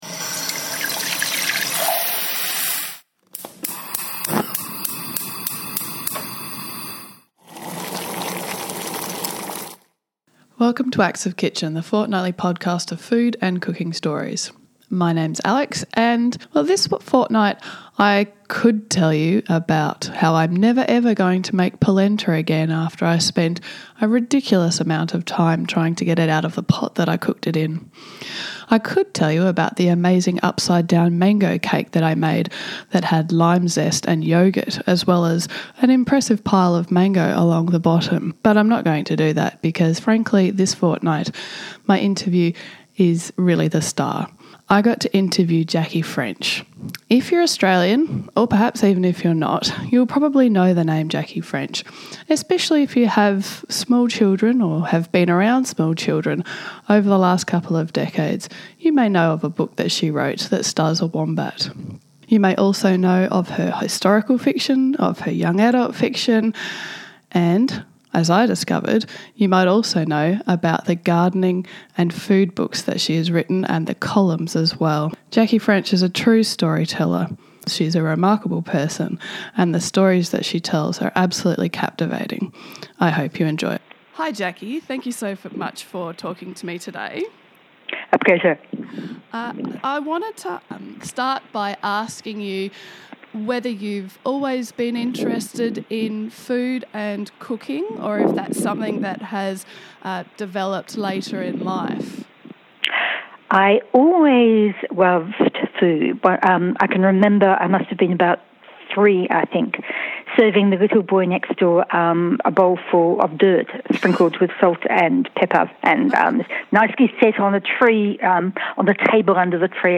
I got to interview Jackie French.